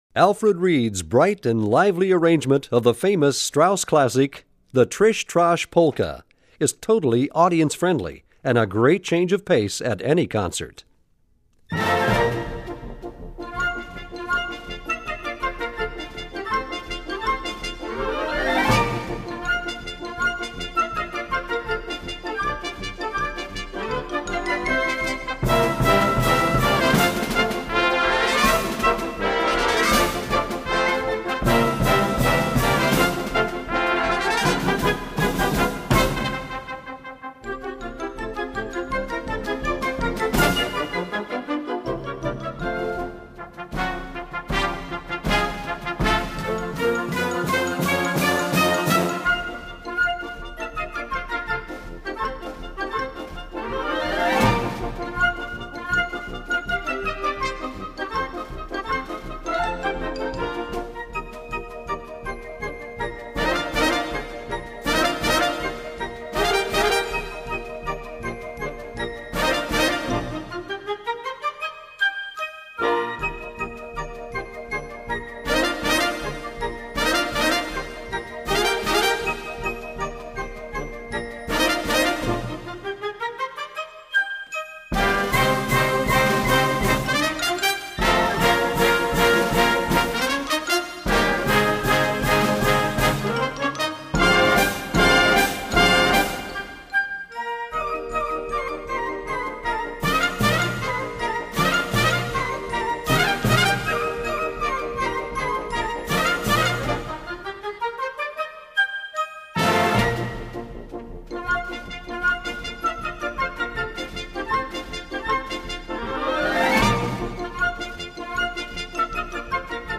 Gattung: Polka
Besetzung: Blasorchester
Bright and lively